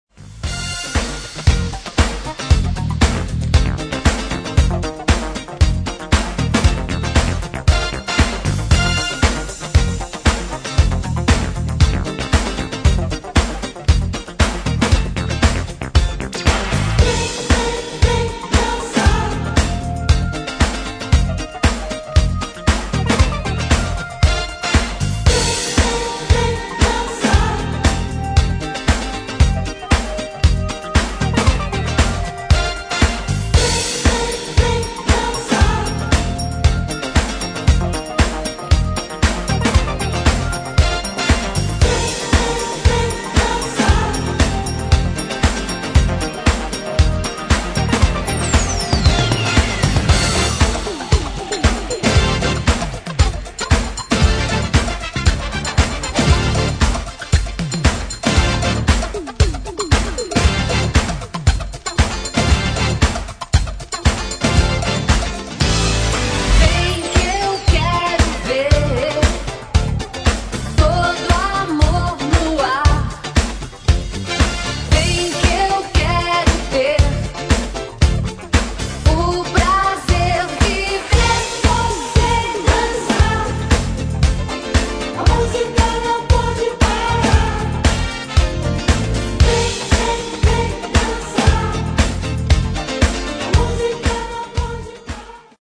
[ DISCO / JAZZ ]